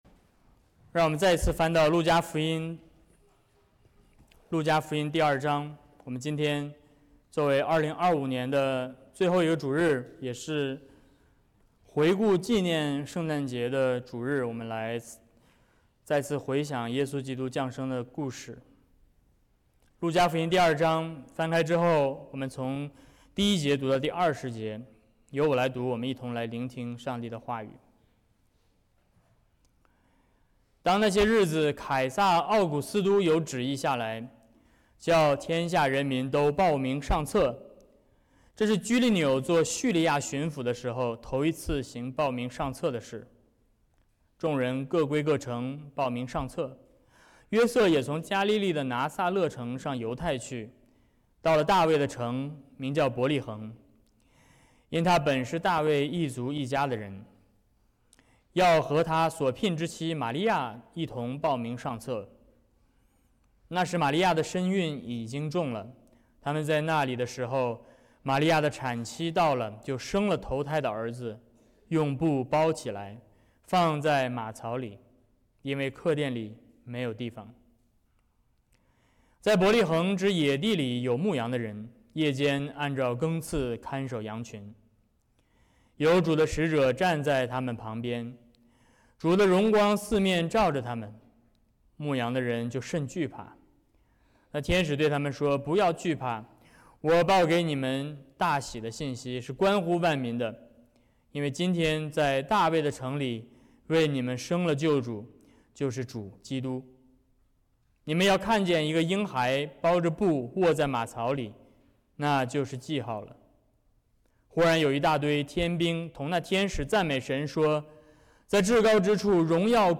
福音书 Service Type: Sermons 2025年12月28日
圣道宣讲